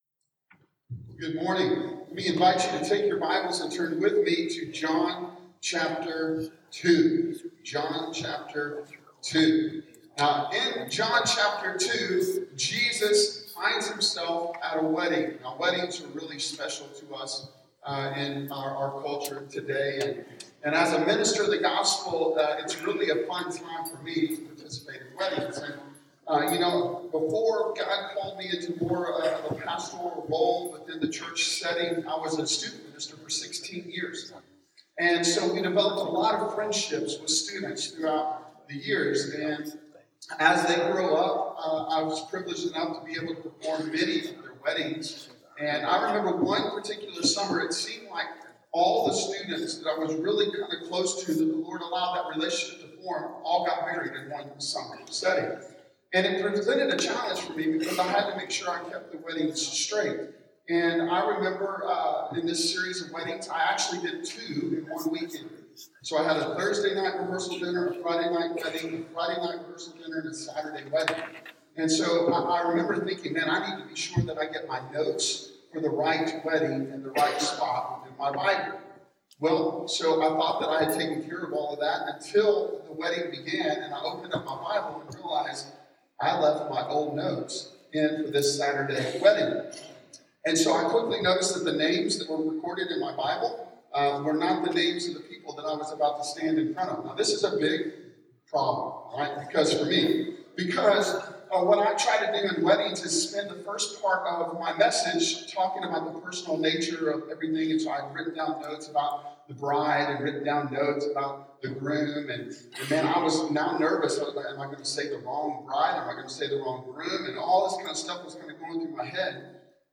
Series: The Gospel of John Sermon Series